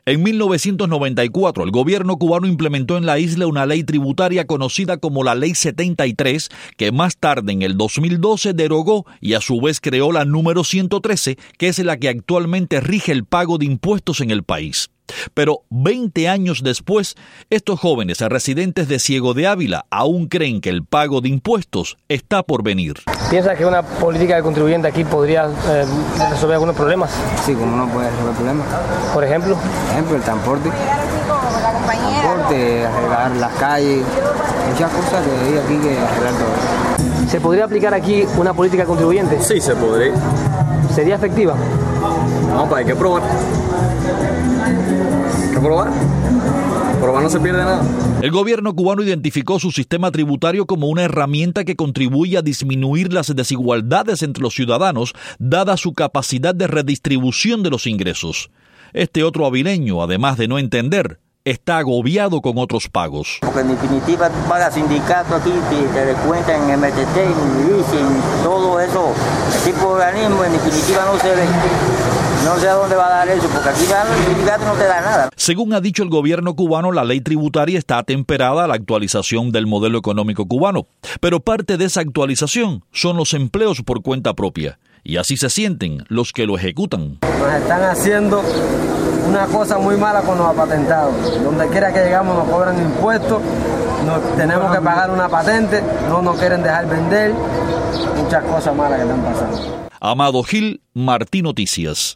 tiene algunas opiniones sobre el tema recogidas por periodistas de la Agencia de Prensa Independiente Palenque Visión, en las calles de Ciego de Ávila.